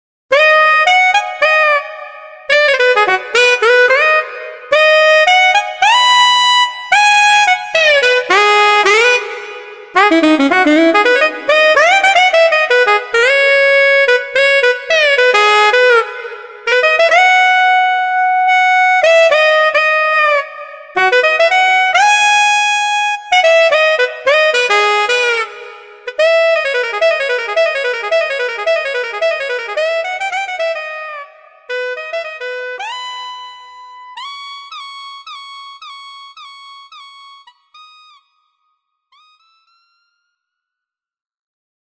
HALion6 : sax
Accent Alto Wheel Growl